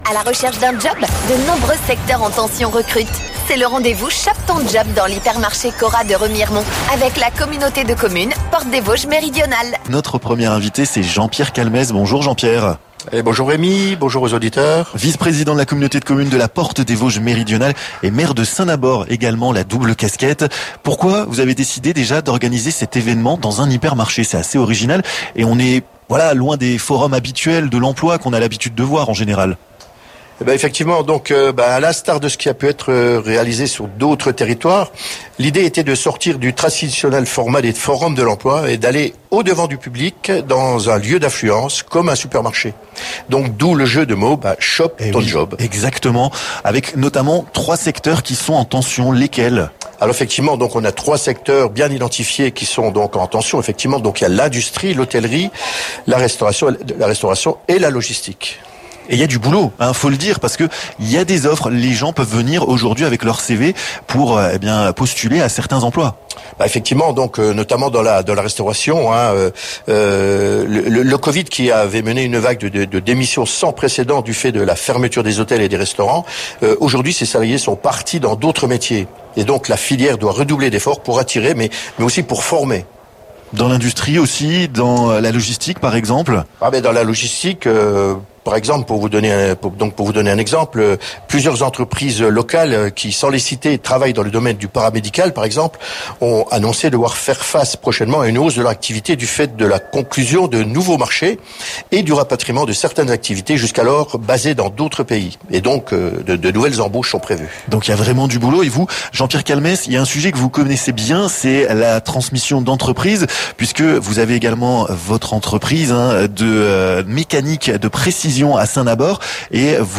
Revivez l'émission "Les Vosges et vous" du vendredi 30 juin en live pour l'événement "Shop ton job" avec la Communauté de Commune de la Portes des Vosges Méridionales !